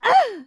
client / bin / pack / Sound / sound / monster / bksoldier / dead_3.wav
dead_3.wav